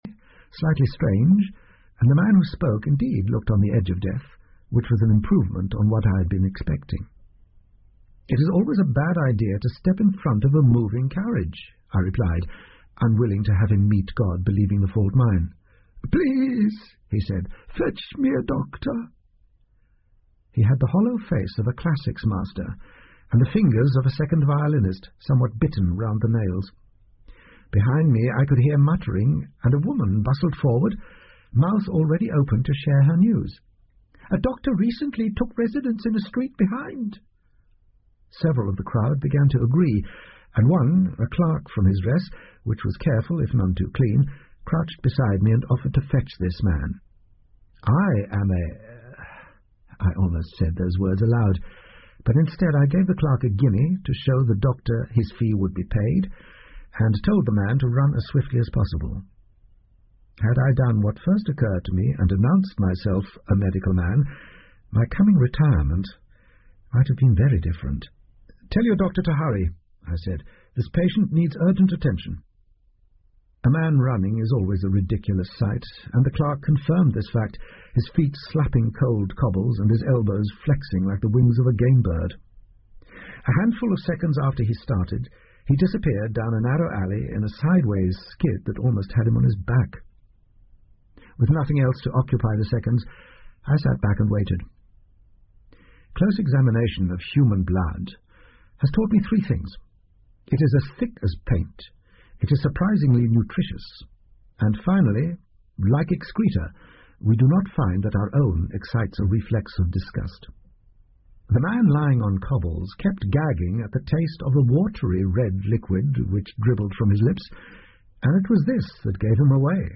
福尔摩斯广播剧 Cult-The Spy Retirement 2 听力文件下载—在线英语听力室